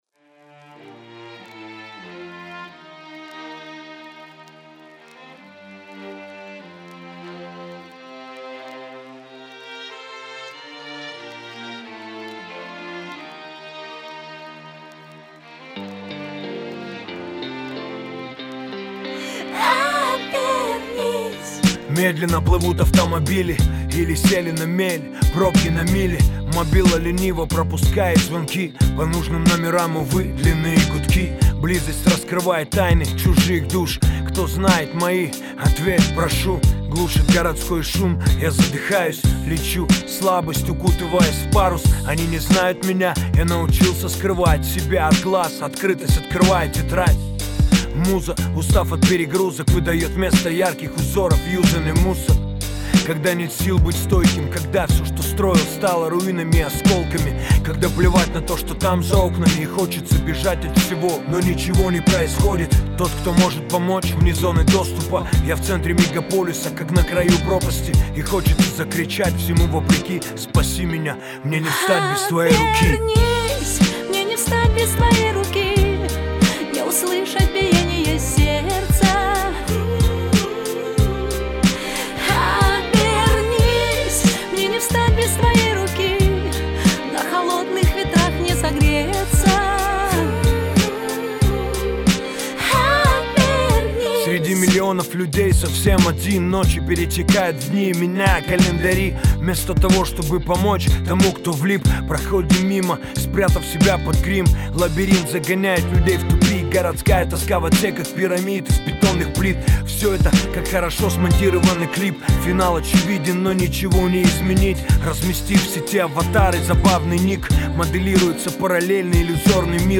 Скачать Rap, Hip-Hop альбомы и треки.